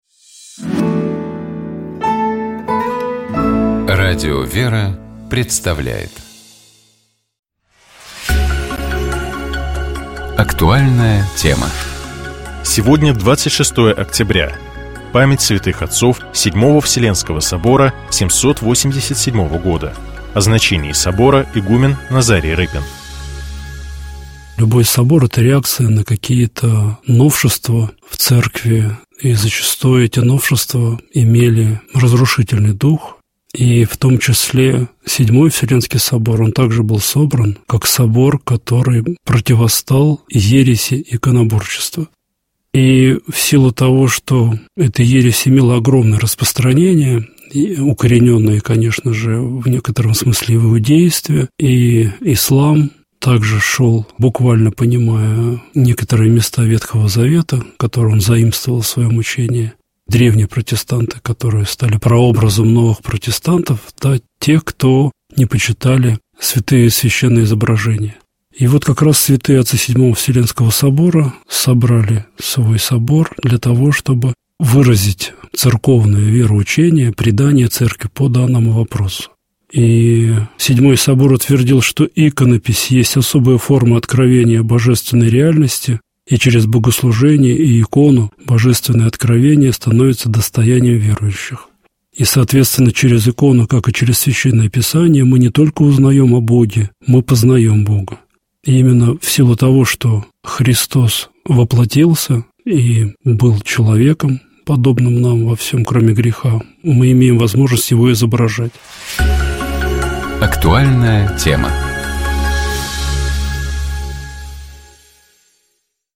Общая теплая палитра программы «Еженедельный журнал» складывается из различных рубрик: эксперты комментируют яркие события, священники объясняют евангельские фрагменты, специалисты дают полезные советы, представители фондов рассказывают о своих подопечных, которым требуется поддержка.